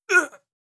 Damage Sounds
15. Damage Grunt (Male).wav